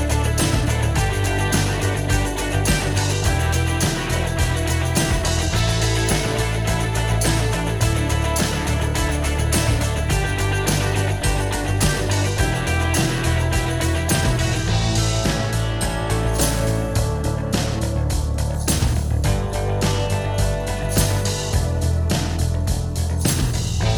Two Semitones Down Indie / Alternative 3:44 Buy £1.50